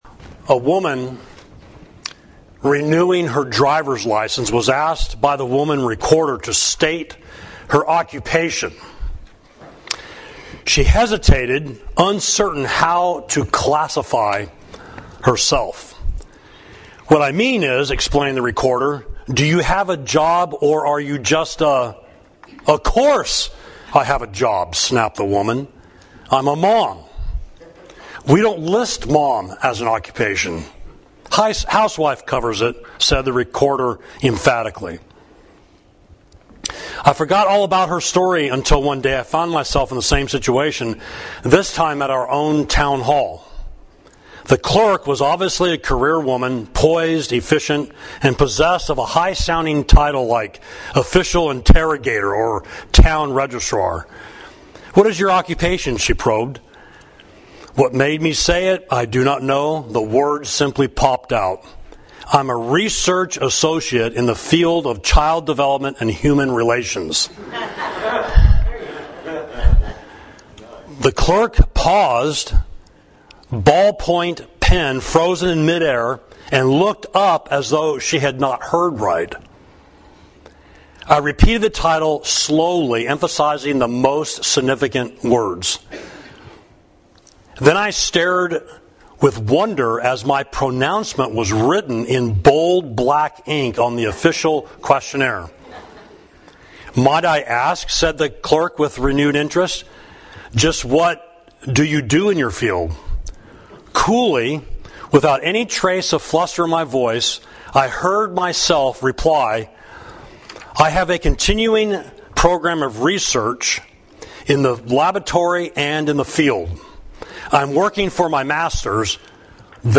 Sermon: Where’s Murver?